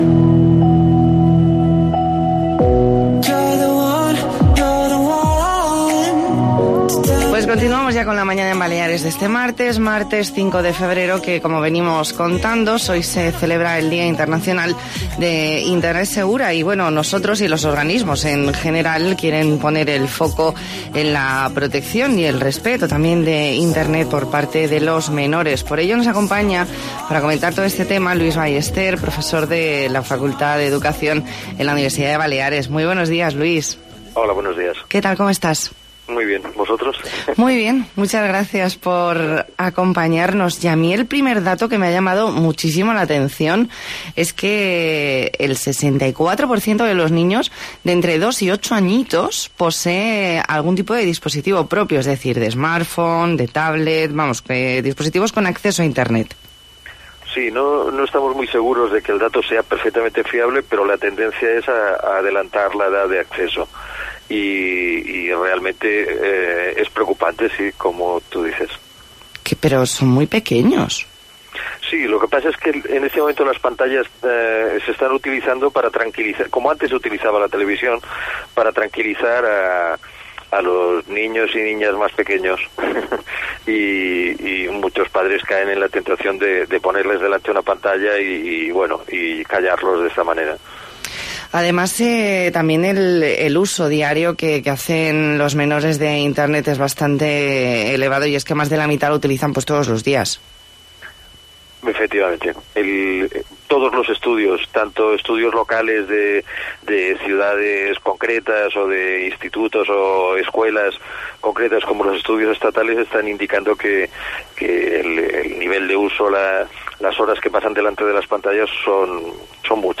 Entrevista en 'La Mañana en COPE Más Mallorca', martes 5 de febrero de 2019.